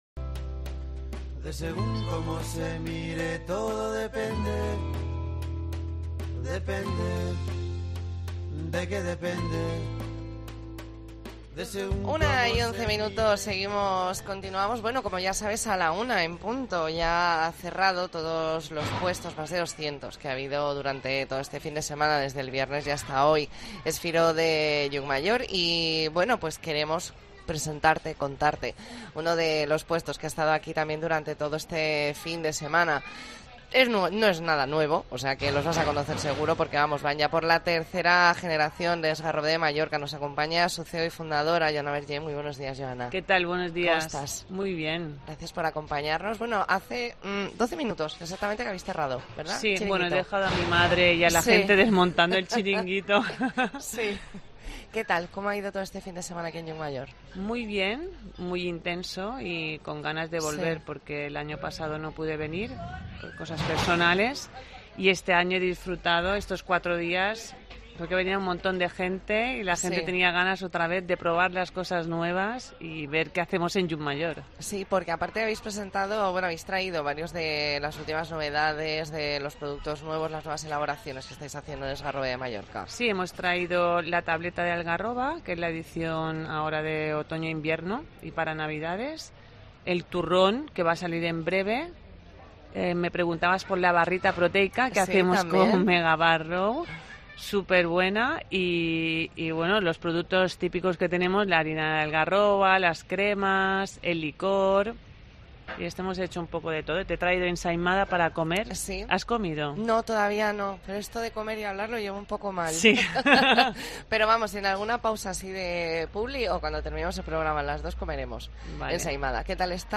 AUDIO: Especial 'La Mañana en Baleares' desde Es Firó de Llucmajor